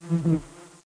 FLY.mp3